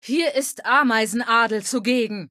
Fallout 3: Audiodialoge